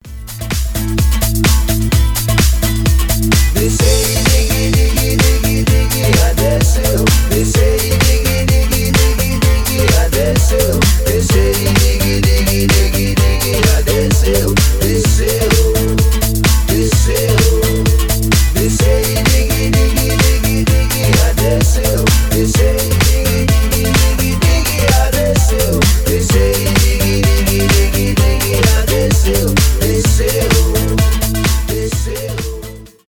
танцевальные , dance pop , зажигательные , house